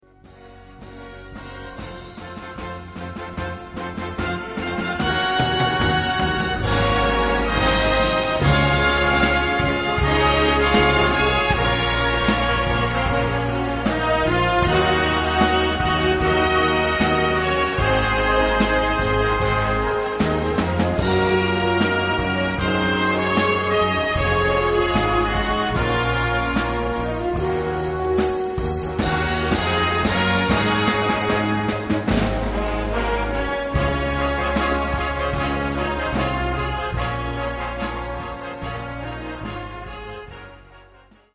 Gattung: Moderne Blasmusik
Besetzung: Blasorchester
Mit Gesangsstimme.